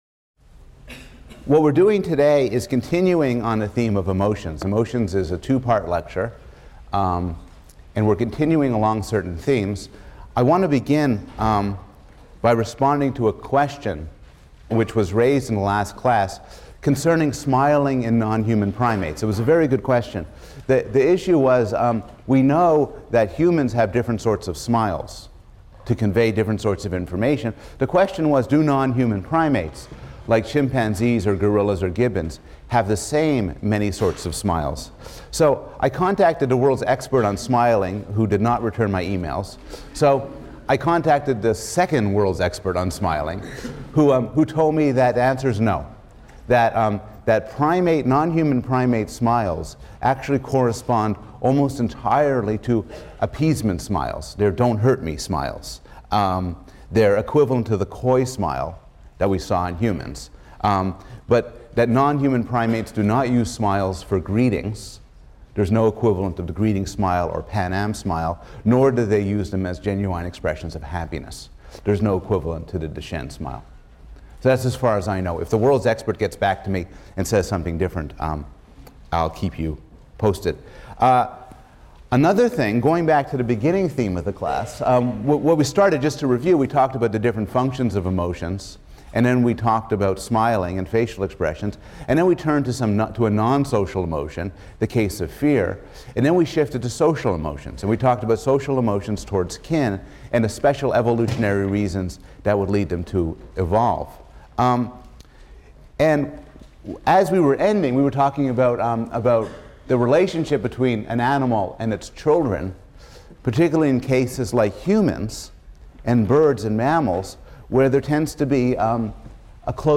PSYC 110 - Lecture 12 - Evolution, Emotion, and Reason: Emotions, Part II | Open Yale Courses